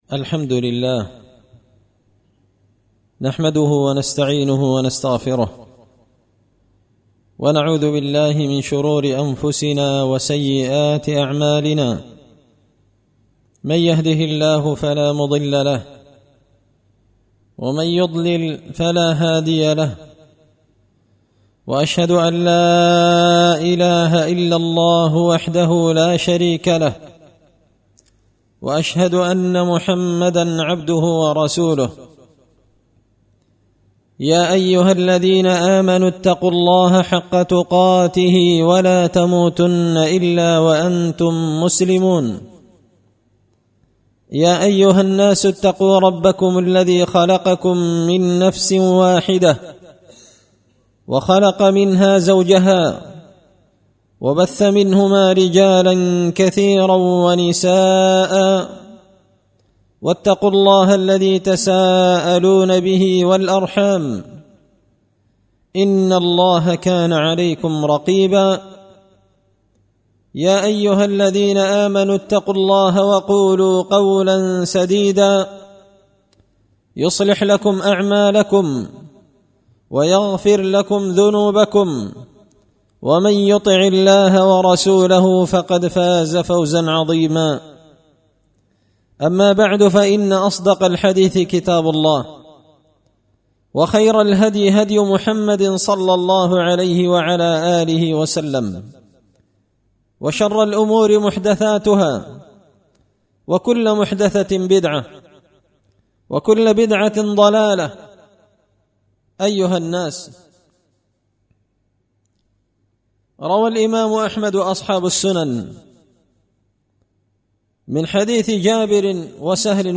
خطبة جمعة بعنوان – هادم اللذات
دار الحديث بمسجد الفرقان ـ قشن ـ المهرة ـ اليمن